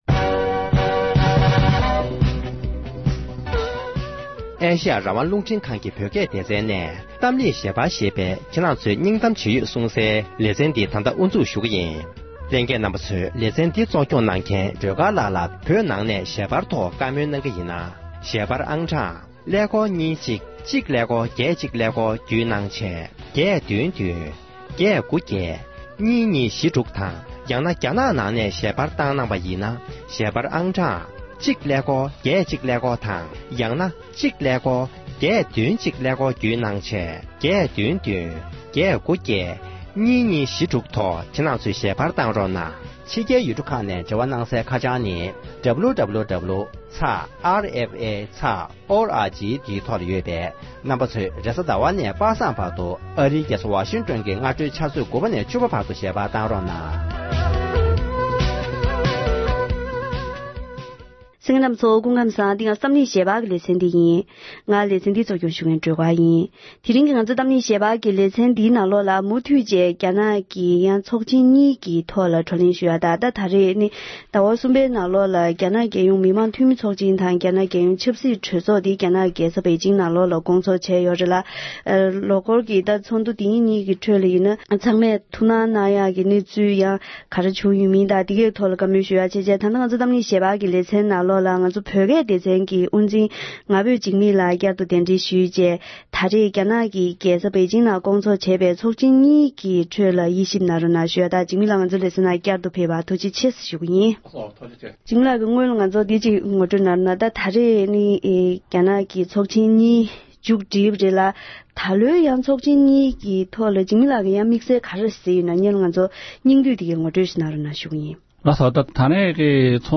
རྒྱ་ནག་གི་ཚོགས་ཆེན་གཉིས་མཇུག་སྒྲིལ་བའི་སྐབས་དེར་འདི་ལོའི་ཚོགས་ཆེན་གཉིས་ཀྱི་གལ་ཆེའི་གནད་དོན་ཐོག་བཀའ་མོལ་ཞུས་པ།